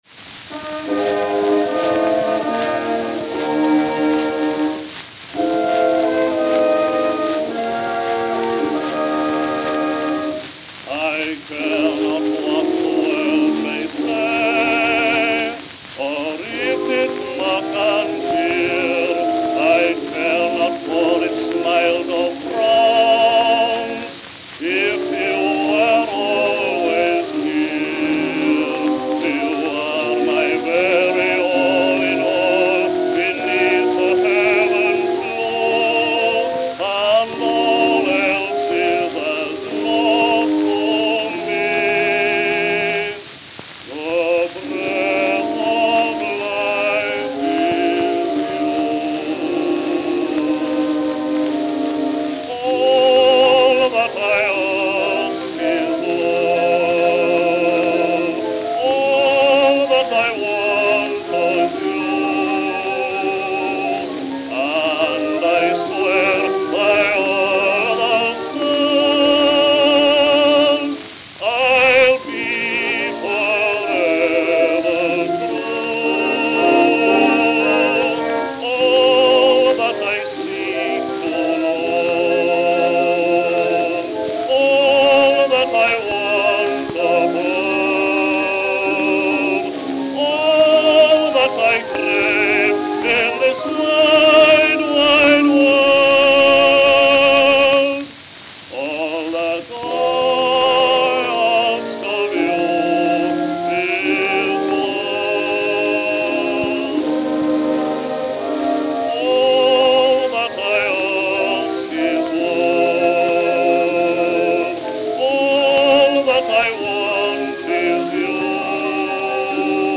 Camden, New Jersey (?)
Note: Worn.